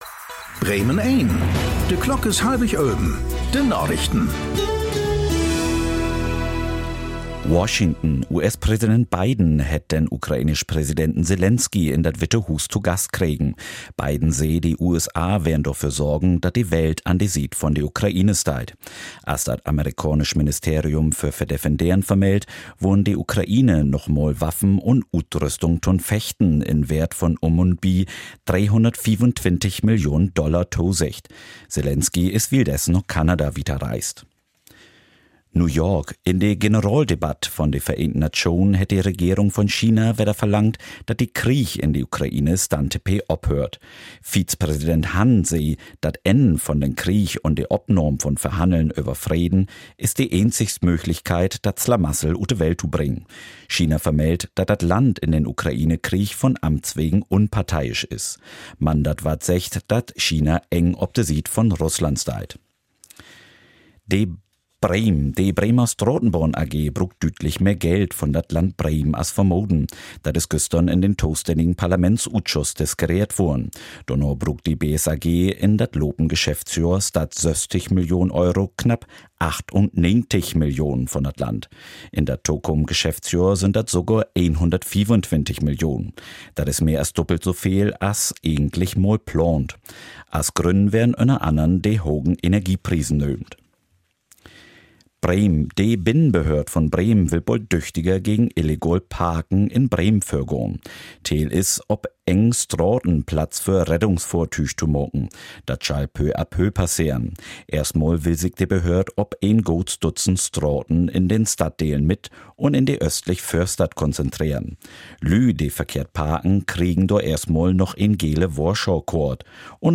Aktuelle plattdeutsche Nachrichten werktags auf Bremen Eins und hier für Sie zum Nachhören.